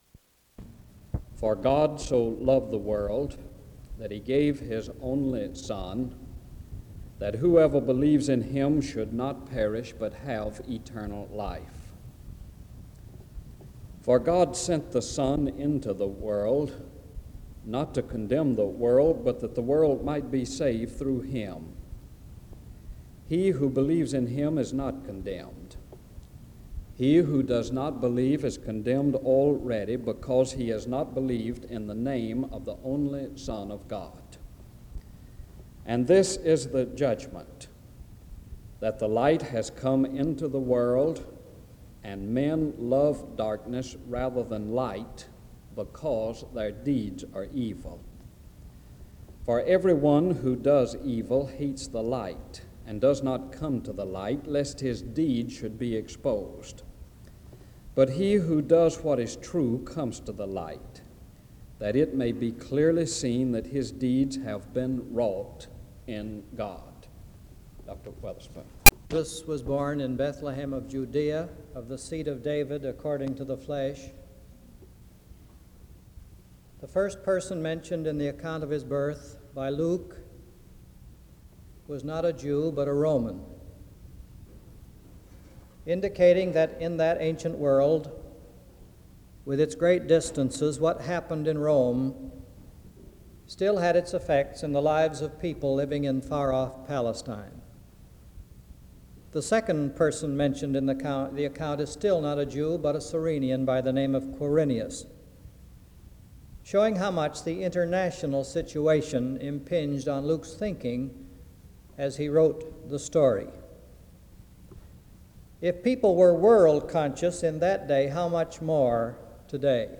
D.C. The service starts with a scripture reading from 0:00-1:08.
SEBTS Chapel and Special Event Recordings SEBTS Chapel and Special Event Recordings